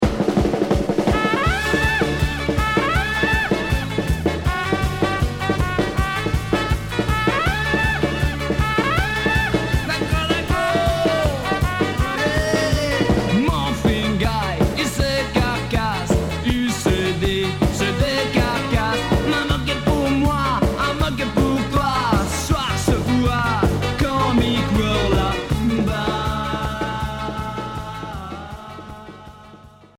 Ska rock Unique 45t retour à l'accueil